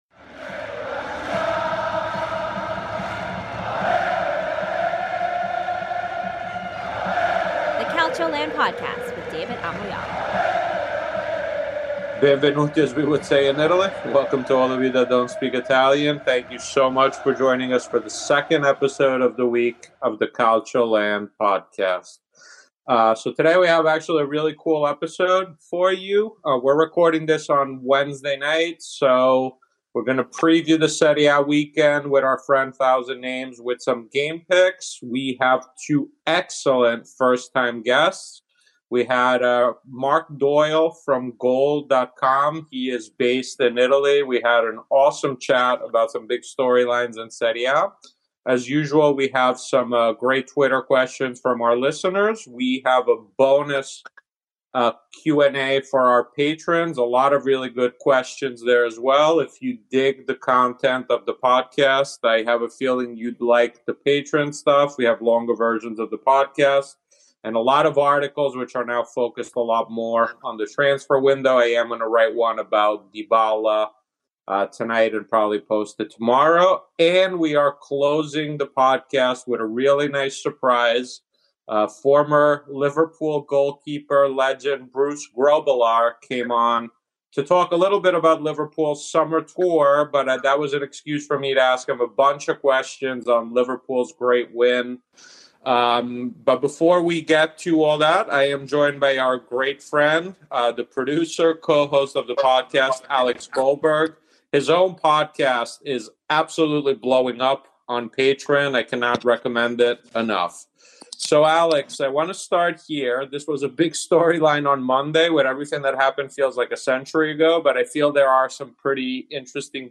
(49:18) - Interview w/ Bruce Grobbelaar (57:23) ... and much more!